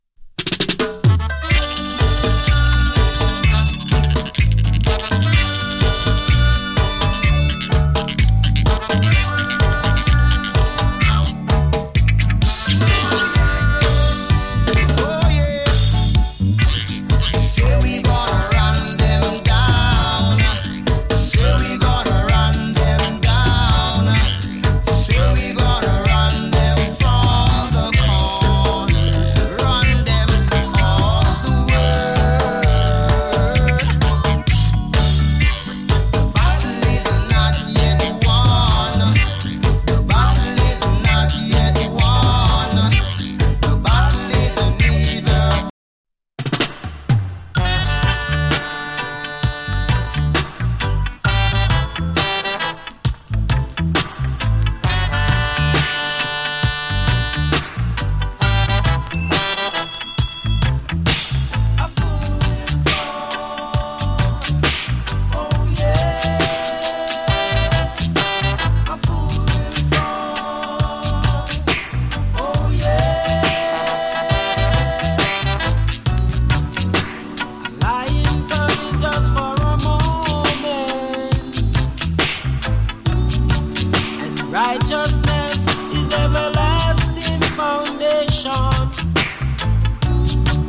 EARLY 80'S DANCE HALL 名曲× 2.